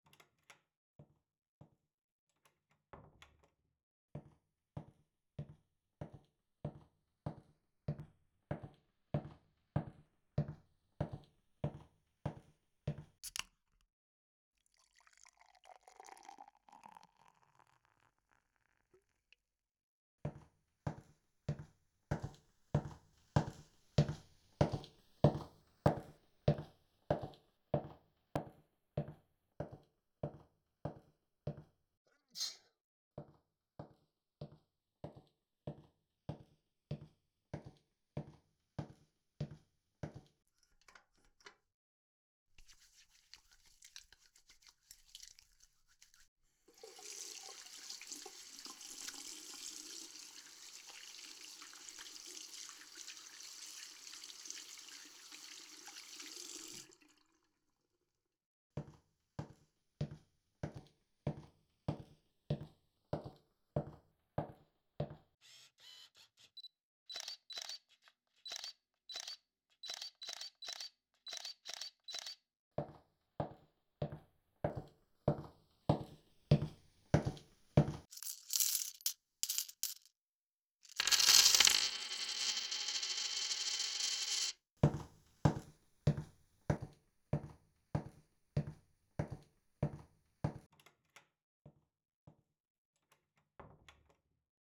Binaural Synthesis (Virtual 3D Audio) Samples: